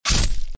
bt_slash_simple2.mp3